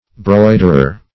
Broiderer \Broid"er*er\, n. One who embroiders.